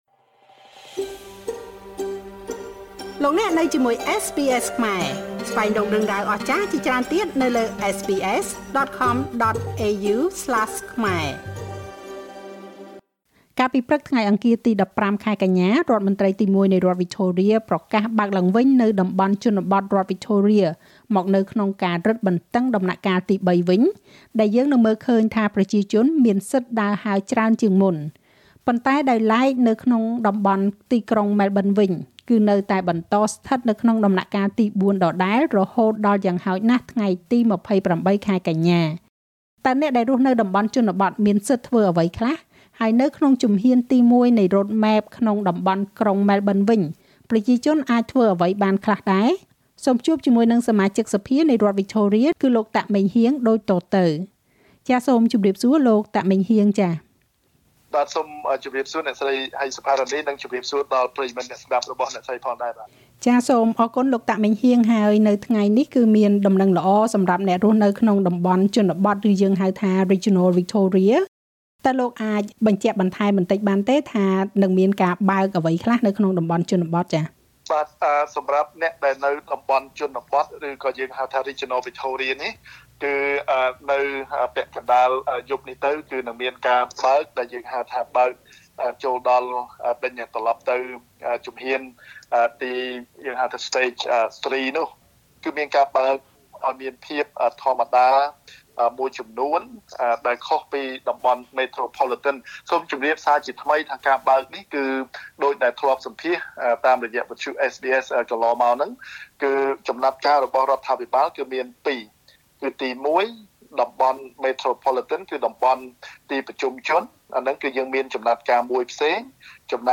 សូមជួបជាមួយសមាជិកសភានៃរដ្ឋវិចថូរៀ លោក តាក ម៉េងហ៊ាង ដូចតទៅ។
Victorian MP Meng Heang Tak at SBS studio in Melbourne Source: SBS Khmer